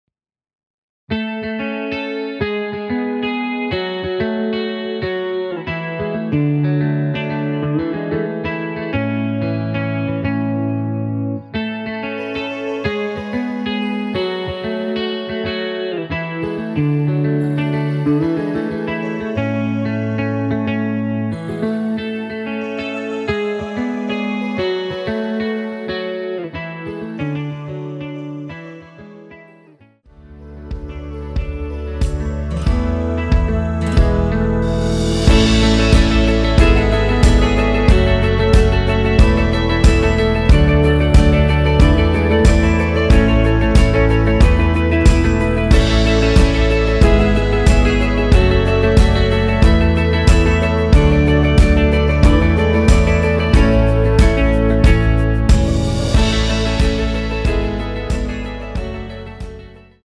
원키 멜로디 포함된 MR입니다.(미리듣기 확인)
노래방에서 노래를 부르실때 노래 부분에 가이드 멜로디가 따라 나와서
앞부분30초, 뒷부분30초씩 편집해서 올려 드리고 있습니다.
중간에 음이 끈어지고 다시 나오는 이유는